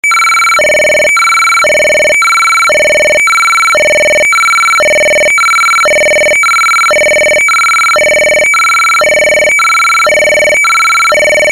Federal Signal Siren Tone UNITROL HI-LO (RUMBLER)